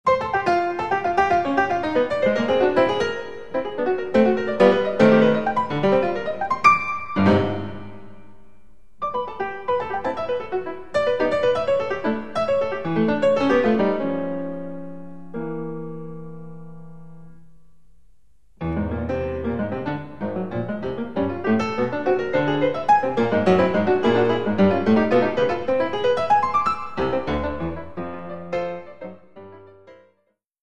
A valuable collection of Australian compositions for piano
A disc of extreme contrasts
Australian, Classical, Keyboard